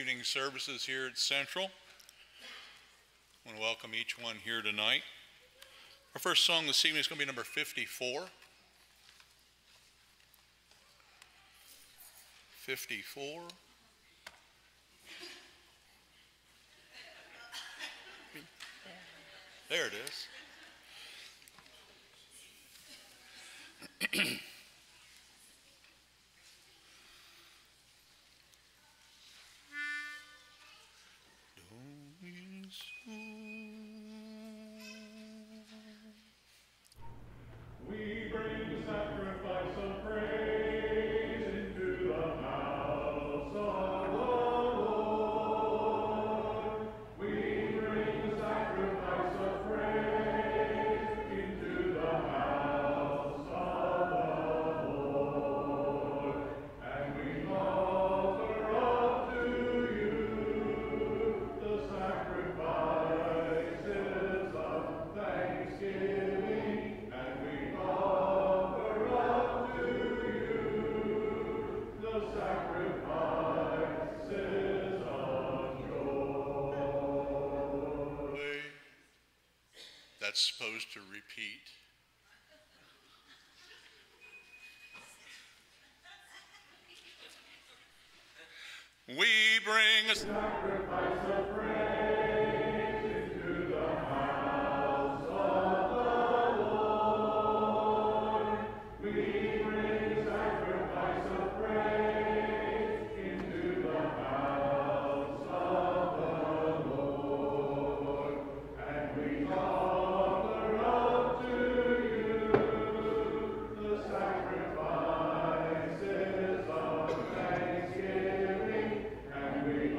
Romans 6:23, English Standard Version Series: Sunday PM Service